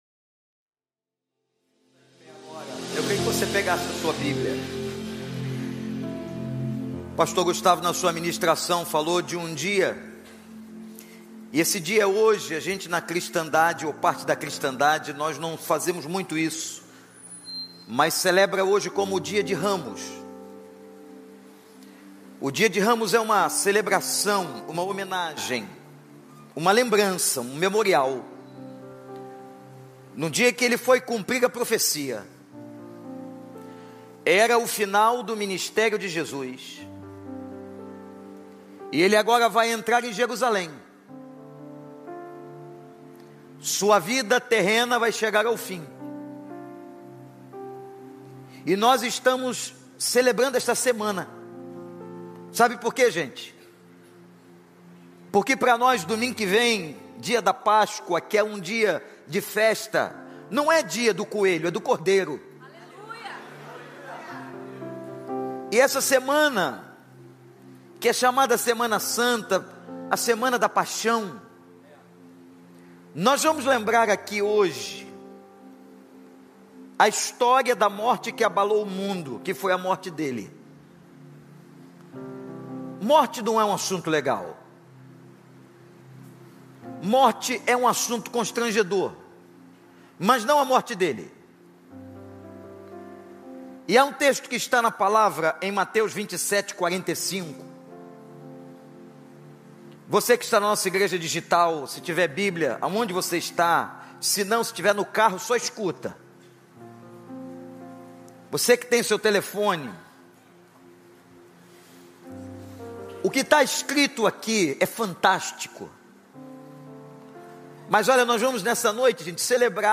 na Igreja Batista do Recreio.